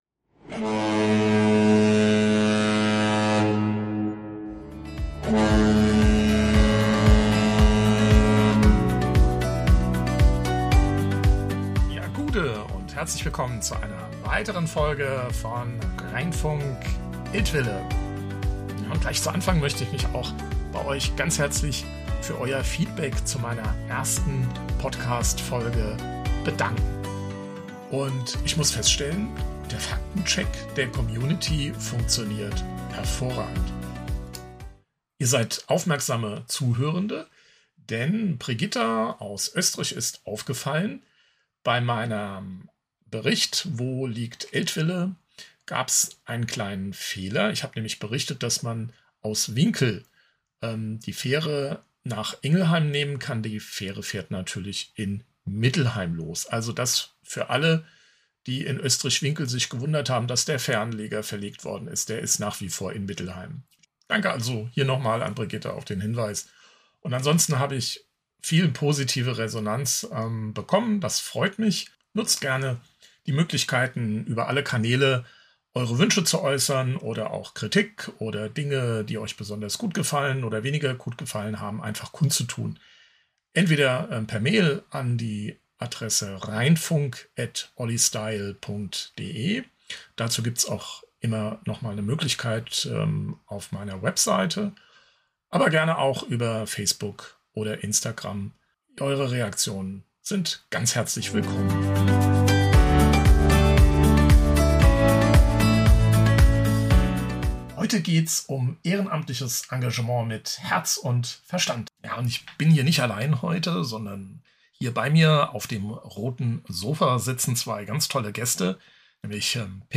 Beschreibung vor 2 Tagen Bei Rheinfunk Eltville kommen engagierte Menschen aus Eltville zu Wort.